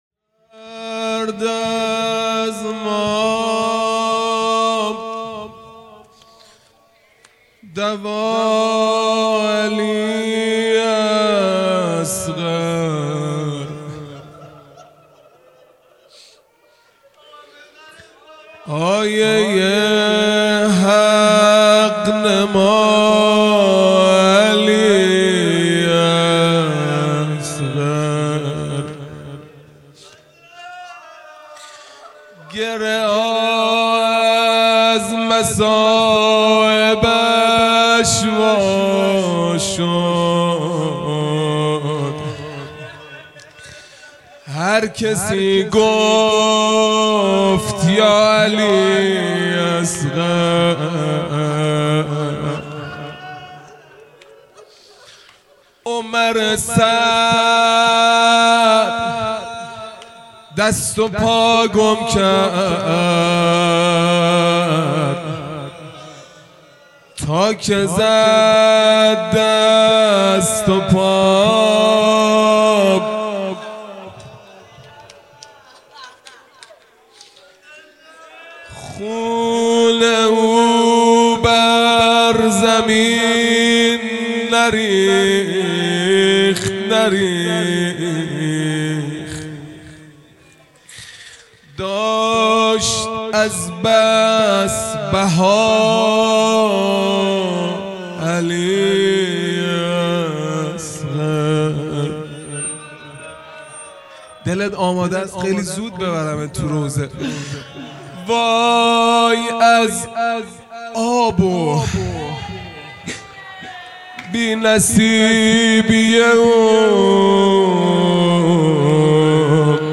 روضه | درد از ما دوا علی اصغر
محرم الحرام ۱۴۴5 | شب هفتم